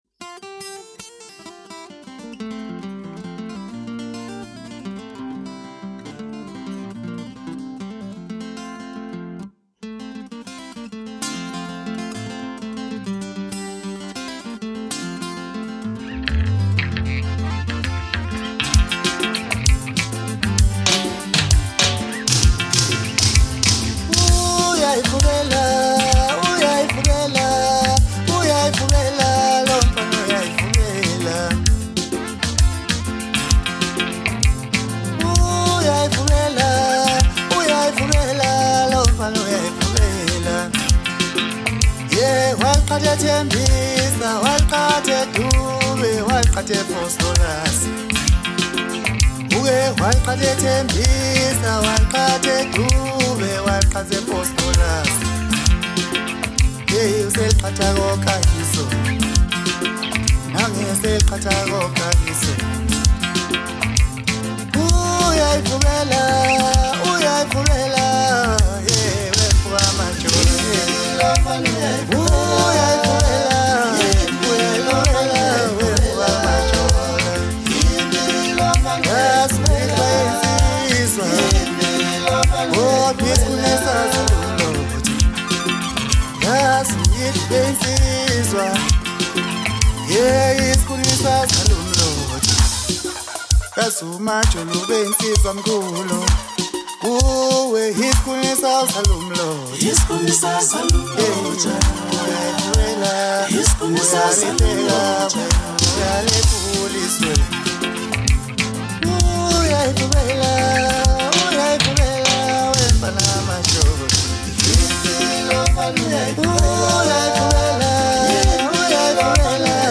• Genre: Maskandi